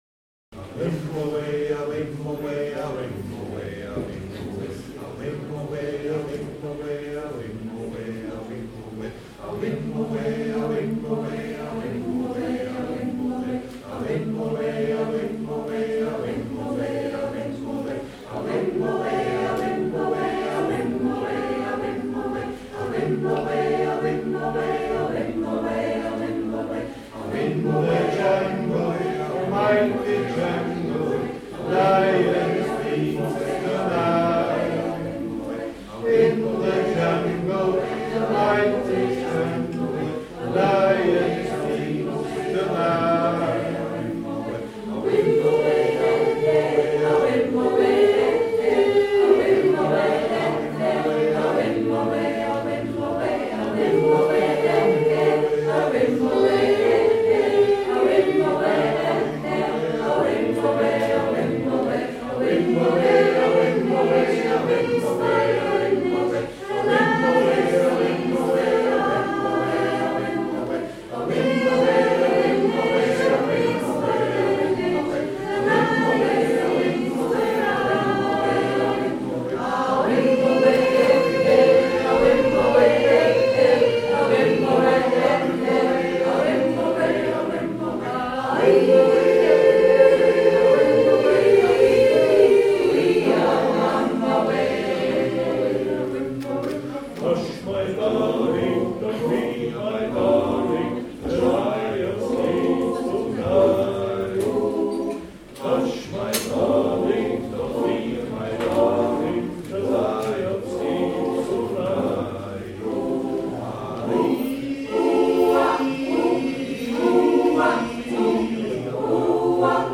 XANGSMEIEREI-Auftritt Tullnerbach 08.10.2016